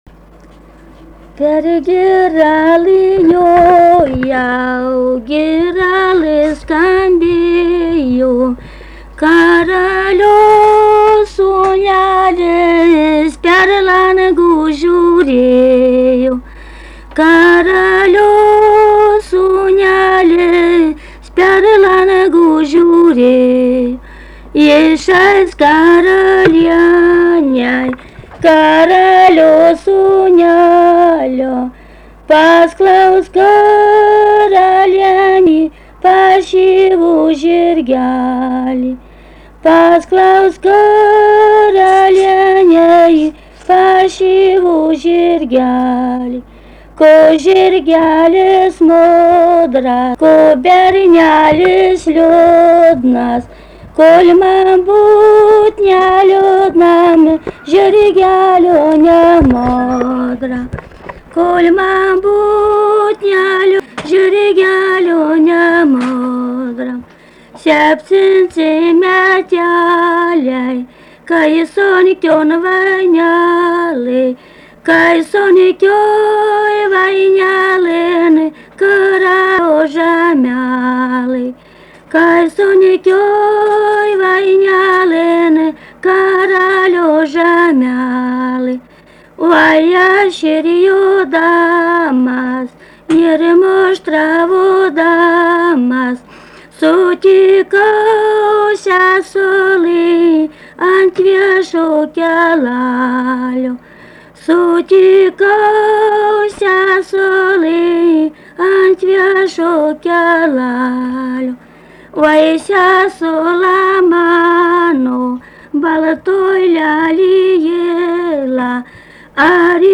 daina, vestuvių
Erdvinė aprėptis Pauosupė Vilnius
Atlikimo pubūdis vokalinis
Pastabos 2 balsai